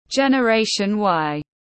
Thế hệ Gen Y tiếng anh gọi là generation Y, phiên âm tiếng anh đọc là /ˌdʒen.ə ˈreɪ.ʃən ˈwaɪ/.
Generation Y /ˌdʒen.ə ˈreɪ.ʃən ˈwaɪ/